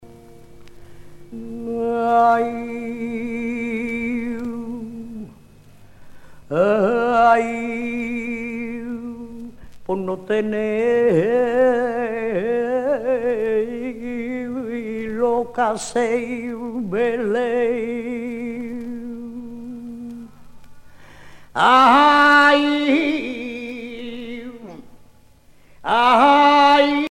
Usage d'après l'analyste circonstance : dévotion, religion ;
Catégorie Pièce musicale éditée